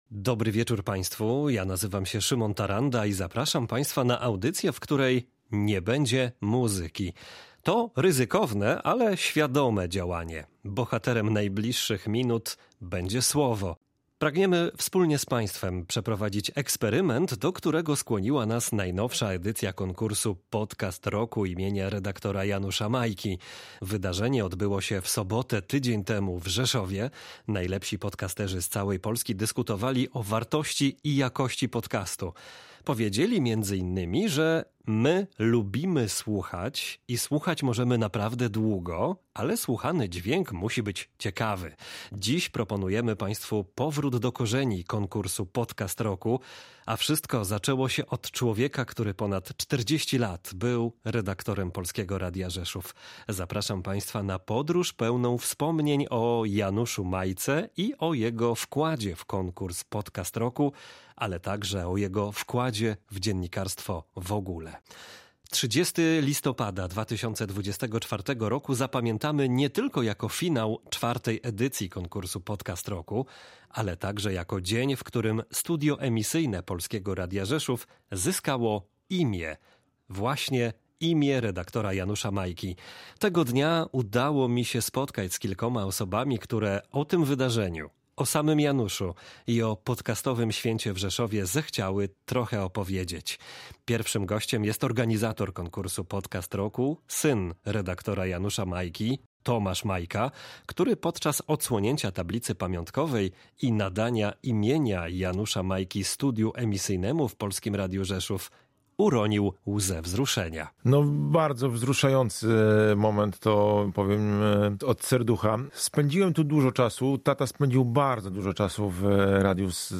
Zapraszamy o wysłuchania audycji, w której nie ma muzyki.